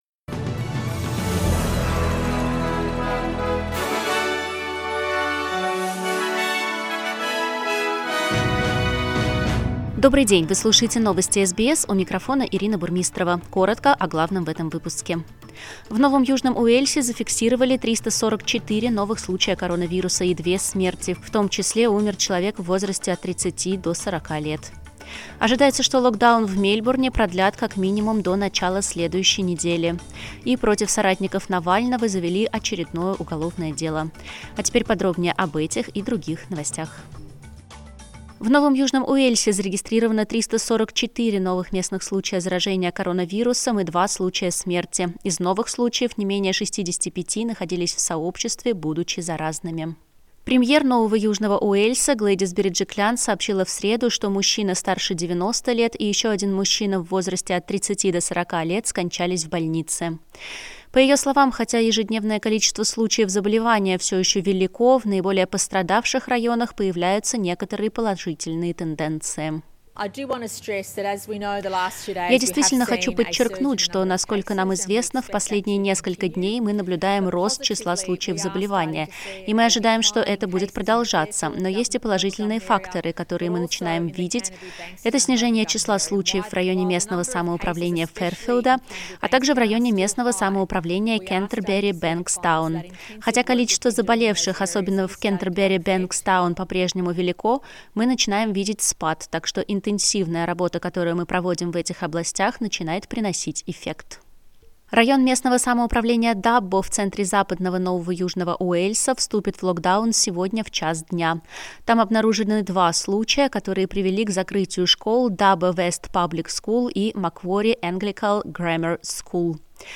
SBS news in Russian - 11.08